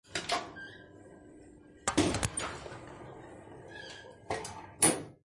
电梯旧门开合
描述：老奥的斯电梯门打开和关闭。
Tag: 电梯门打开 摇摇欲坠 接近